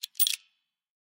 Звуки бинокля
В стационарный бинокль на обзорной площадке засунули монету